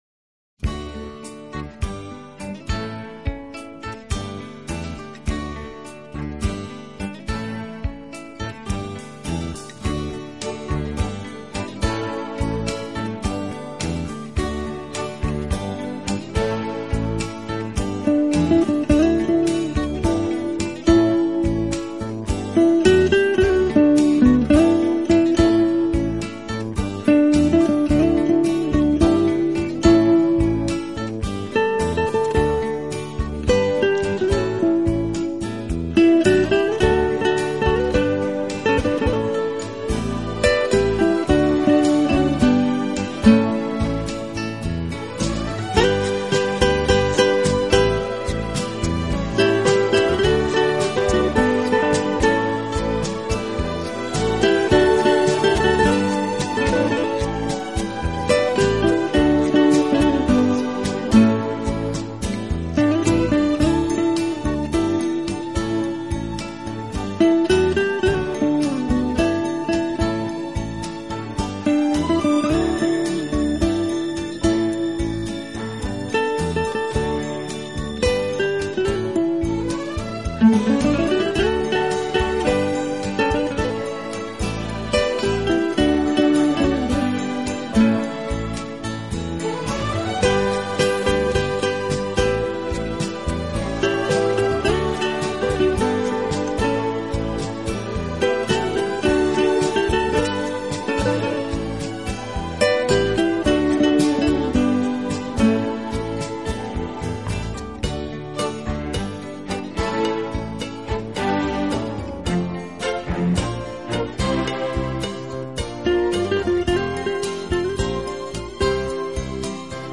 深厚的古典风格
種音乐表现技巧，美婉浪漫的旋律，滋润着人们纯情的梦，充满了诗的意境。